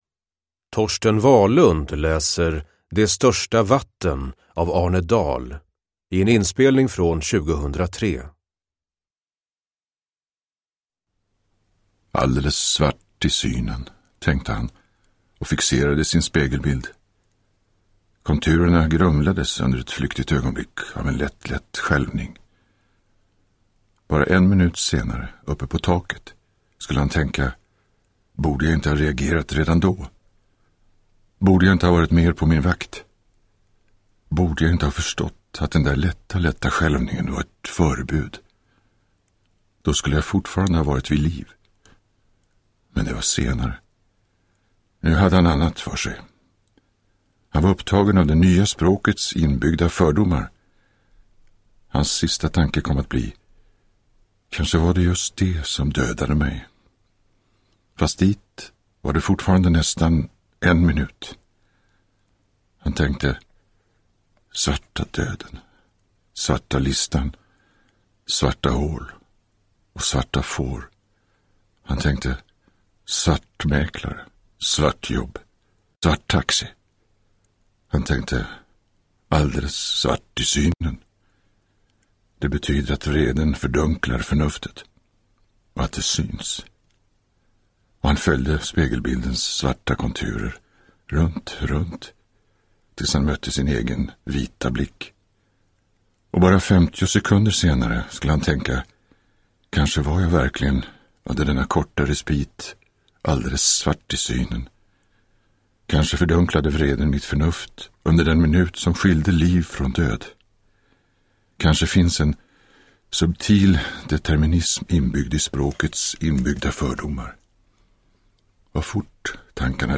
Uppläsare: Torsten Wahlund
Ljudbok